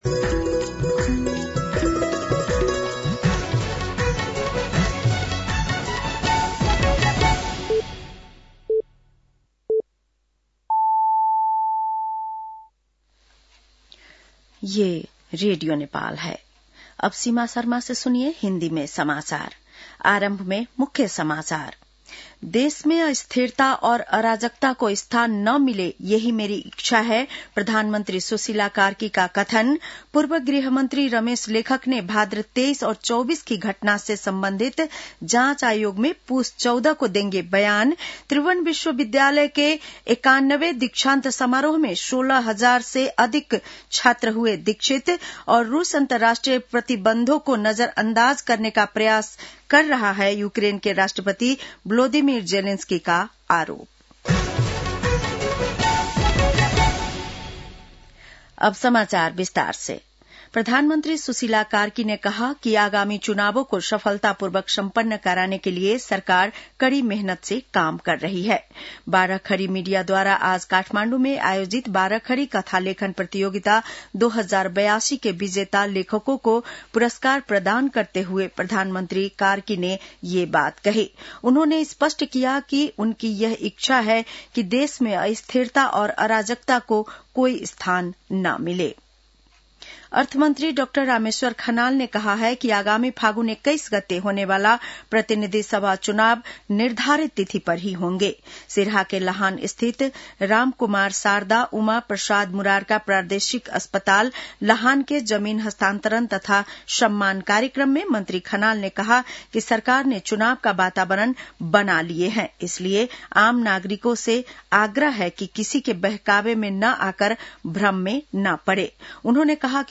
बेलुकी १० बजेको हिन्दी समाचार : १० पुष , २०८२